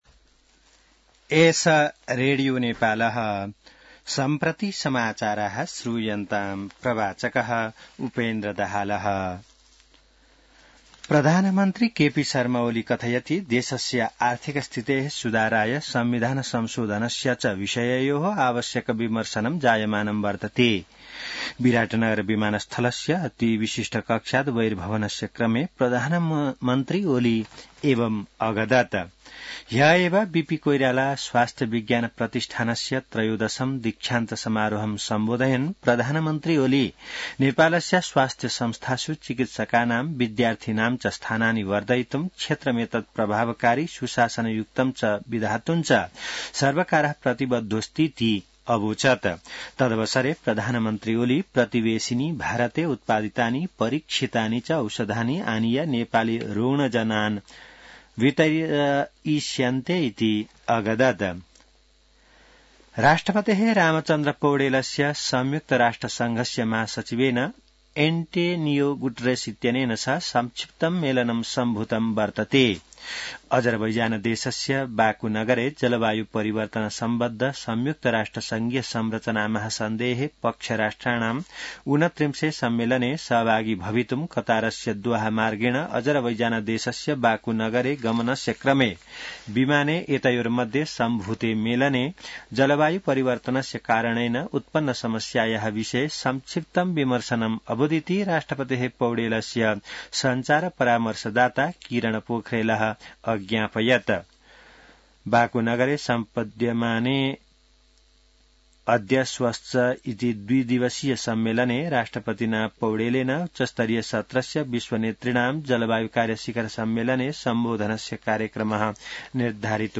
संस्कृत समाचार : २८ कार्तिक , २०८१